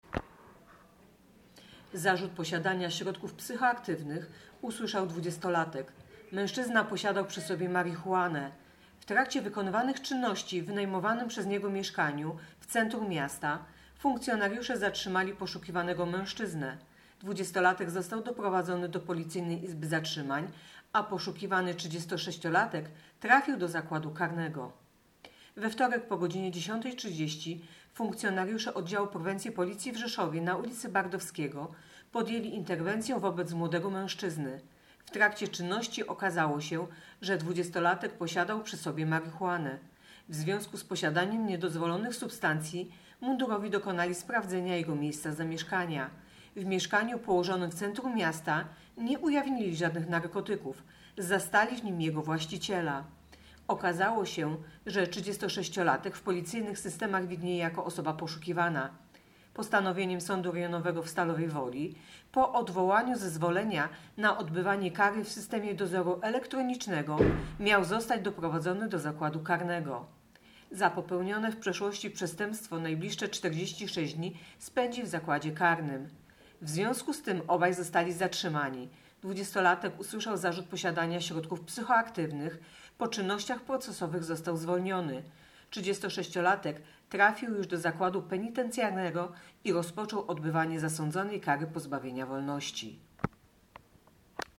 Opis nagrania: Informacja pt. Jeden miał narkotyki, drugi był poszukiwany.